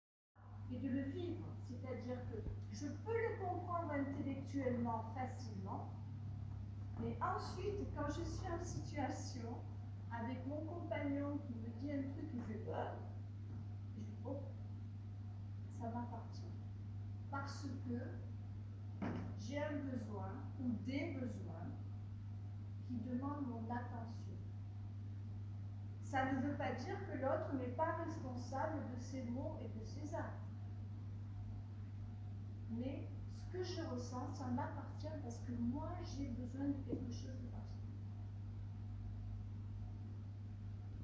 Une très belle soirée animée par l’association Au fil de Soi 05. Une présentation de toute la richesse de la communication bienveillante et consciente.
Rendez-vous à la Salle l’Impro à Gap.